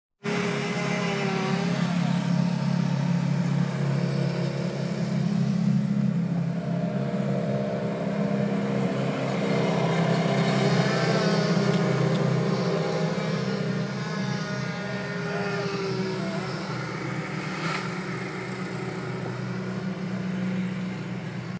Tree Trimmer
tree-trimming.m4a